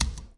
电脑键盘 " 办公室电脑键盘 26
描述：电脑键盘按键